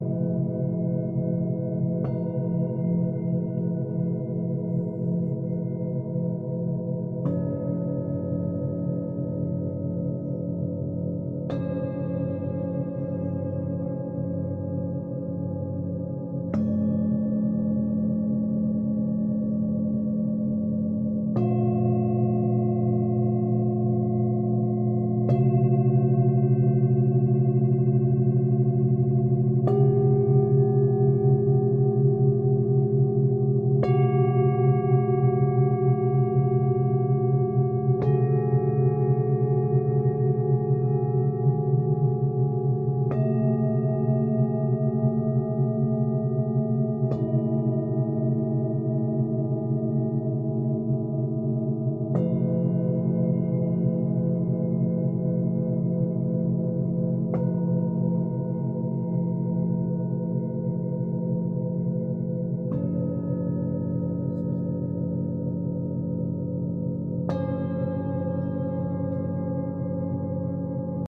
Sound Bath Recording